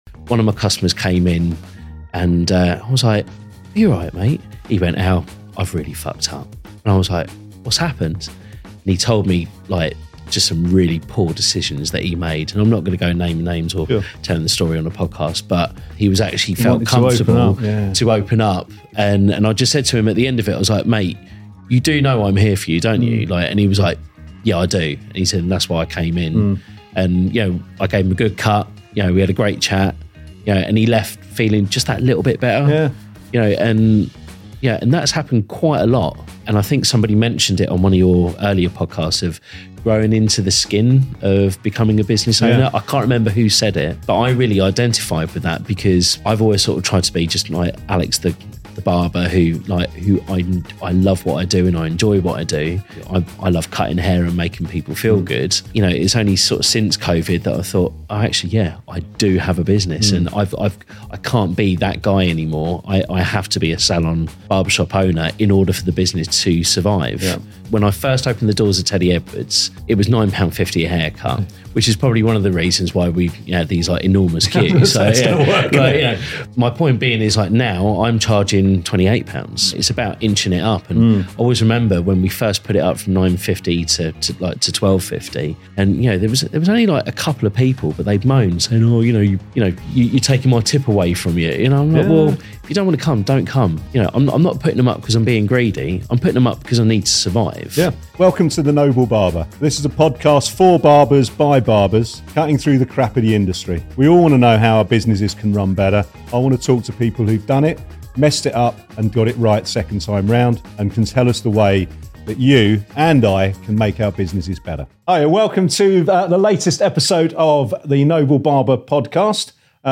Joined weekly by industry professionals to talk about barbering, becoming and maintaining a barber shop, hiring, employment, money and cutting through the crap of the industry.